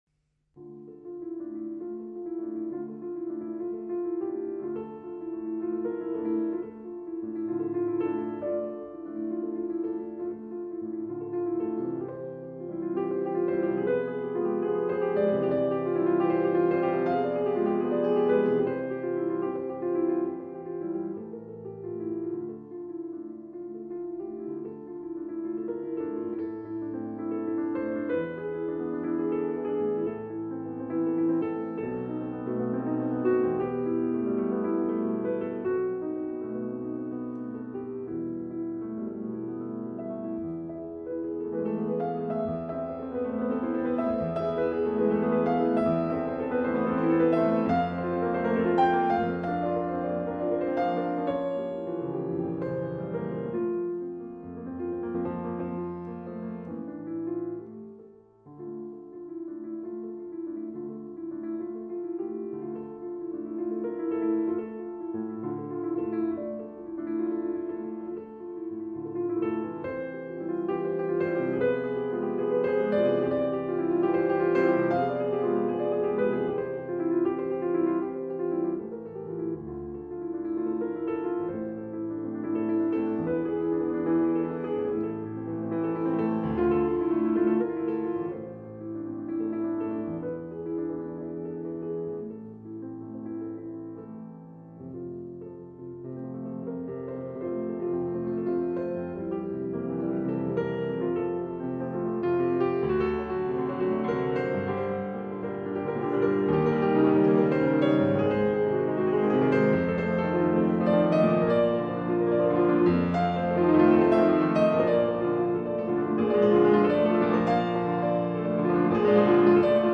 Fortepijoninė muzika